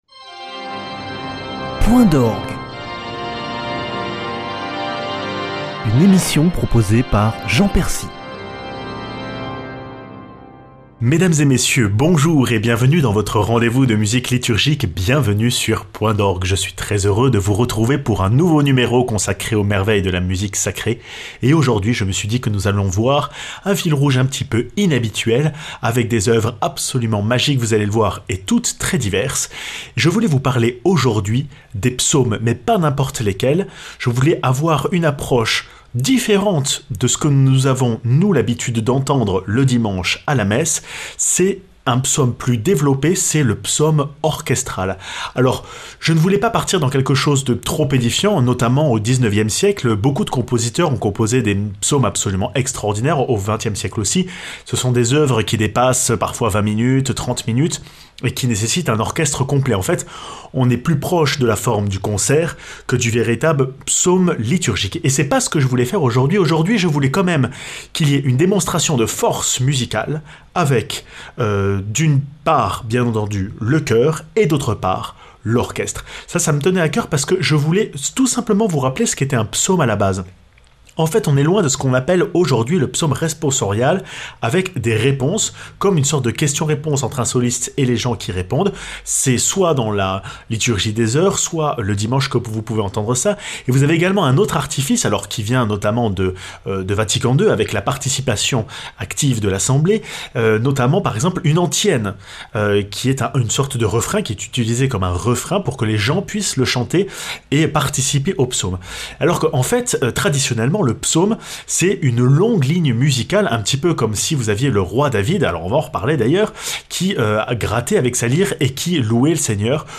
Initialement composés d’un seul tenant, de grands compositeurs ont, au cours de l’histoire, eu à cœur de faire des psaumes alliant parfois chœurs et orchestres conduisant à de magnifiques développements.